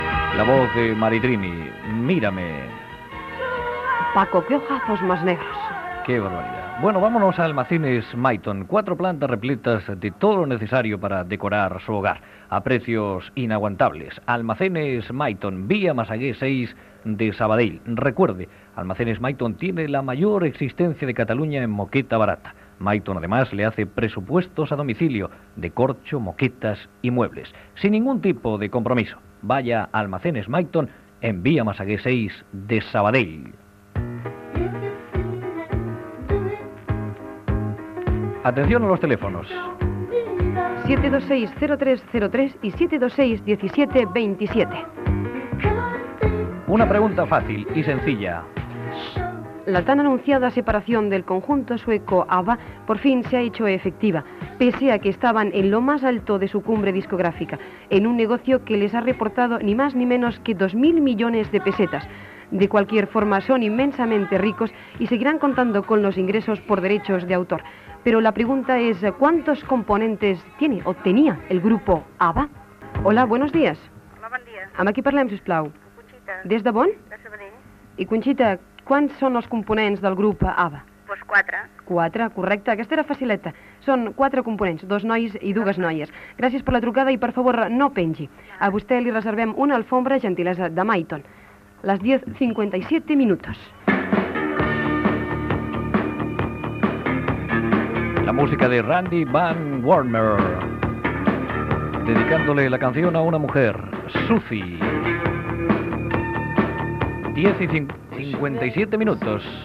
Publicitat, telèfons de participació, pregunta als oients sobre el grup musical Abba, que s'ha separat, trucada d'una oïdora, hora i tema musical